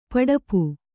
♪ paḍapu